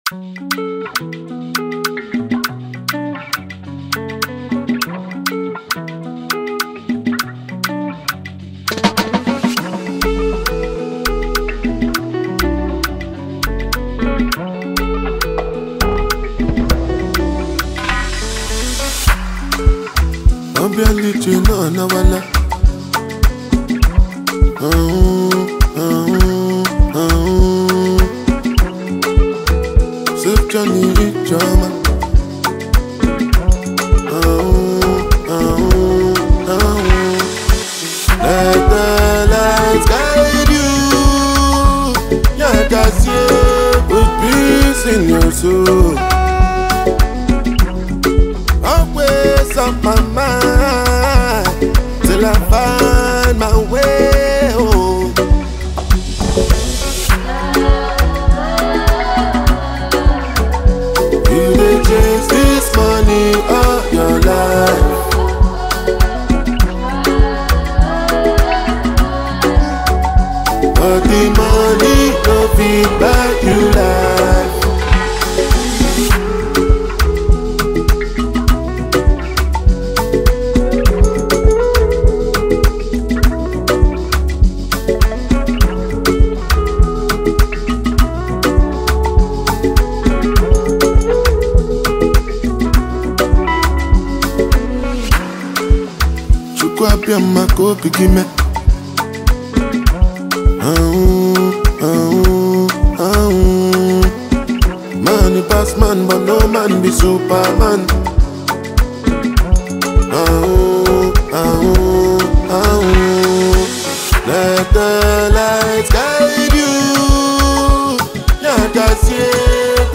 Afrobeats
heartfelt lyrics, delivering a powerful track